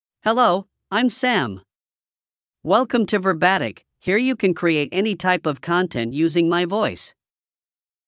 MaleEnglish (Hong Kong SAR)
Sam is a male AI voice for English (Hong Kong SAR).
Voice sample
Sam delivers clear pronunciation with authentic Hong Kong SAR English intonation, making your content sound professionally produced.